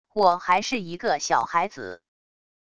我还是一个小孩子wav音频生成系统WAV Audio Player